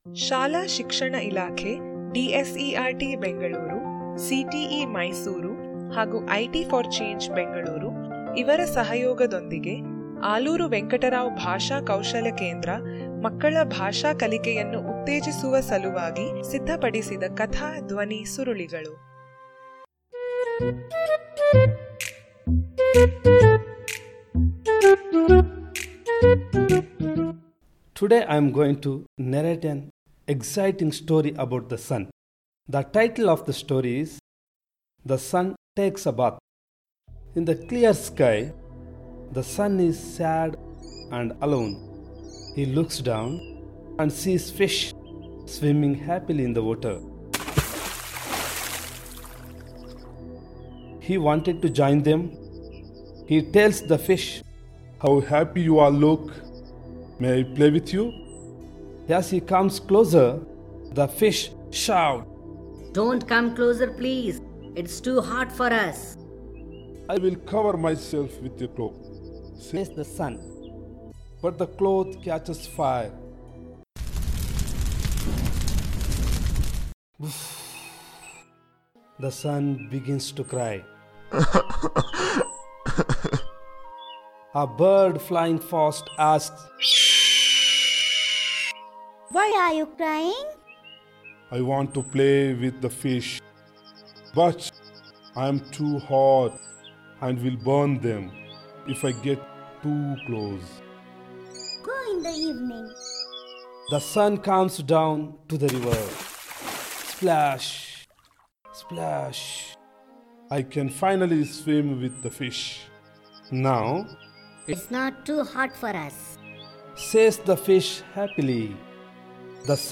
Audio story link